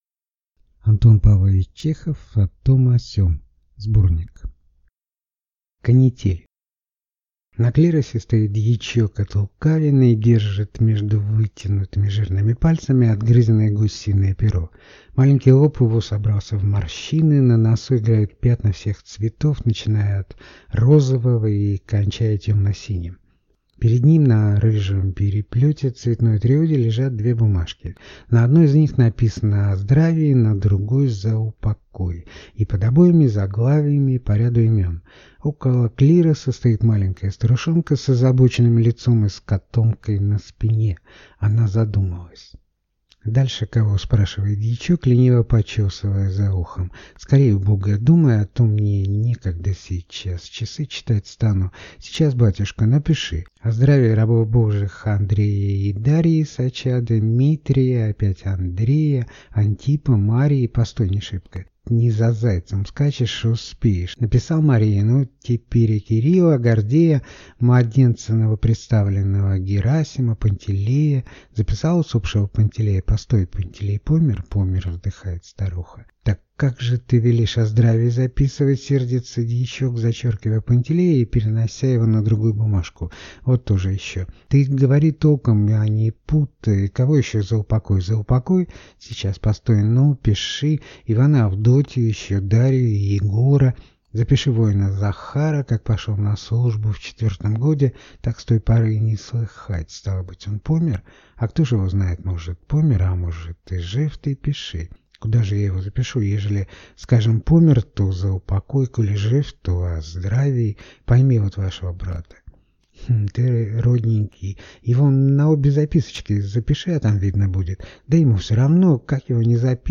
Аудиокнига О том, о сём | Библиотека аудиокниг